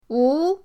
wu2.mp3